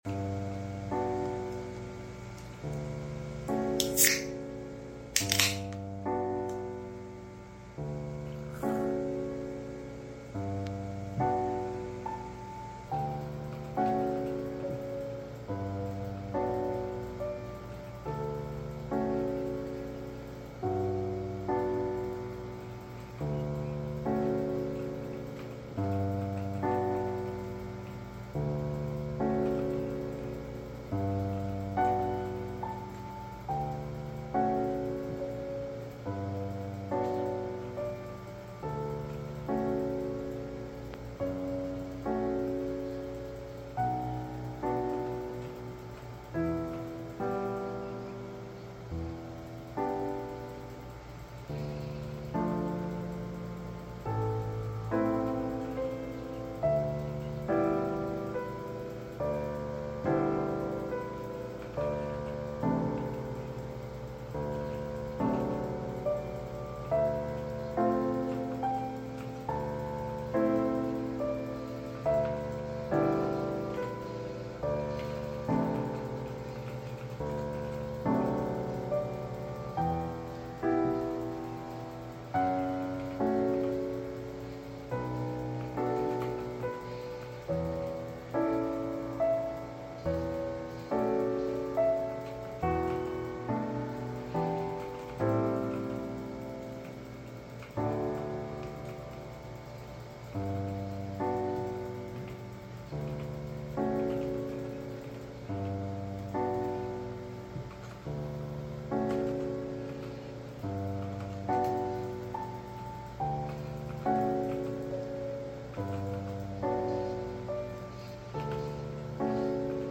DOTA 2 Late Night ASMR Sound Effects Free Download